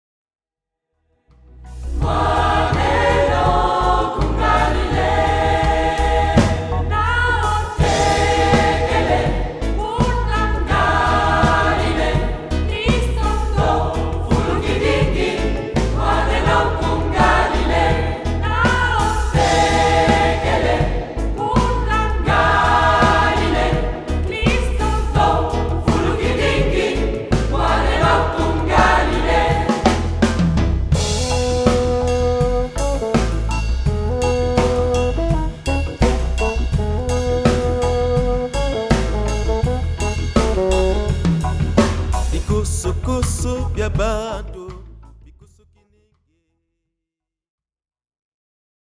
Voici un concert donné dans la cathédrale de Chartres.
Format :MP3 256Kbps Stéréo